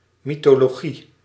Ääntäminen
Synonyymit mythographie Ääntäminen France: IPA: [mi.tɔ.lɔ.ʒi] Haettu sana löytyi näillä lähdekielillä: ranska Käännös Ääninäyte Substantiivit 1. mythologie {f} Suku: f .